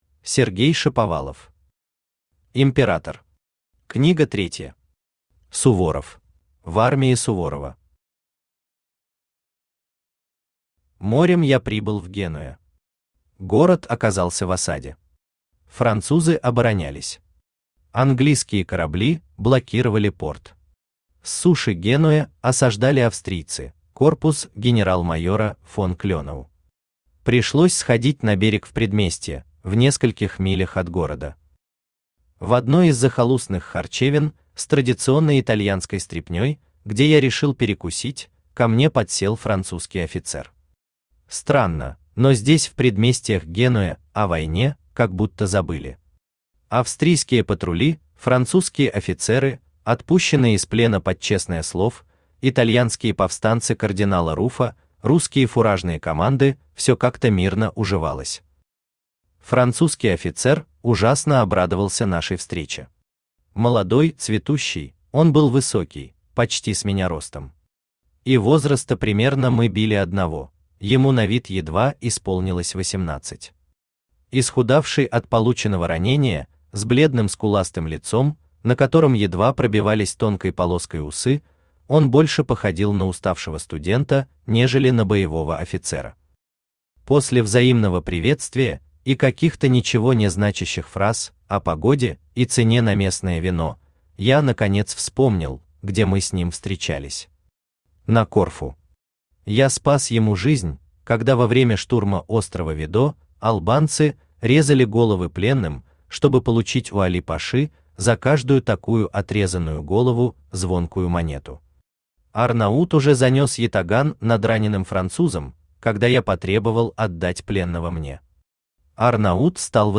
Аудиокнига Император. Книга третья. Суворов | Библиотека аудиокниг
Суворов Автор Сергей Анатольевич Шаповалов Читает аудиокнигу Авточтец ЛитРес.